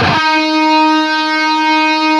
LEAD D#3 LP.wav